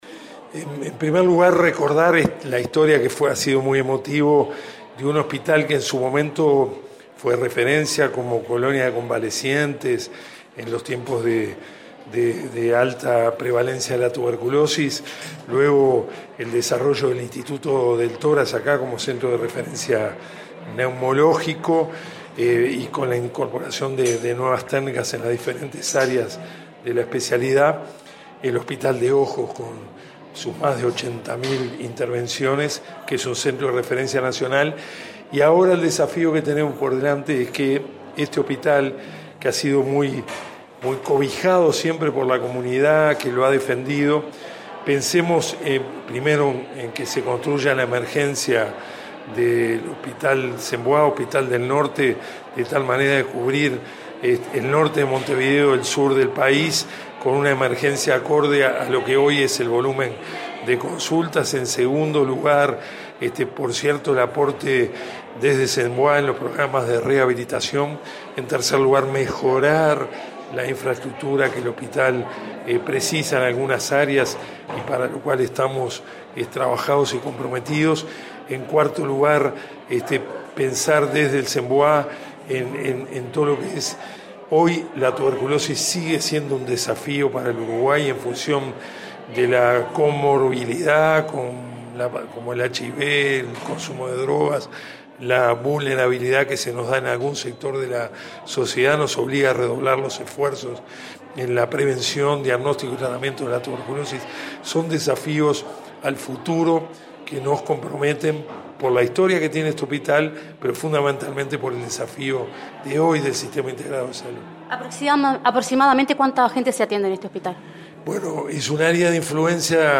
En el marco de los festejos por los 90 años del Hospital Saint Bois, el presidente de ASSE, Marcos Carámbula, subrayó que el desafío actual es mejorar la infraestructura de la emergencia para cubrir el norte de Montevideo y el sur del país, profundizar los programas de rehabilitación y el tratamiento de tuberculosis.